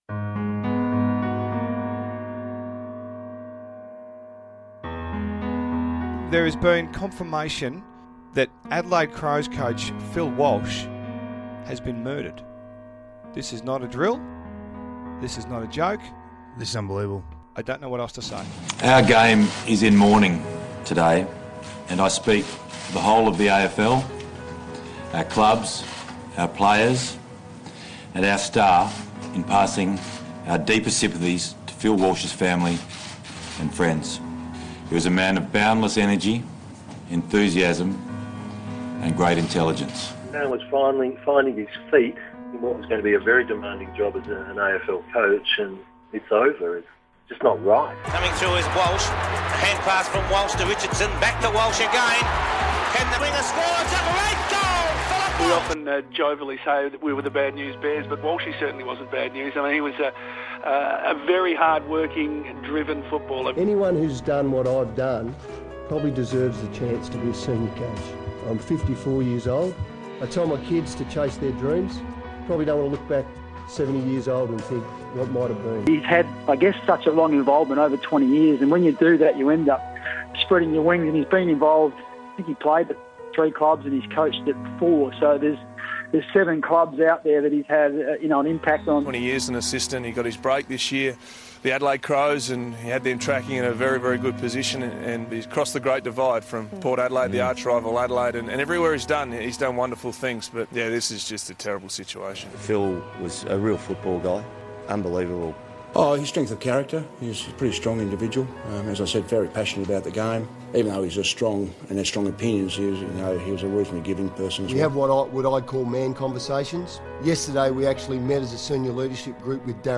Listen to an emotional audio tribute to fallen Crows coach Phil Walsh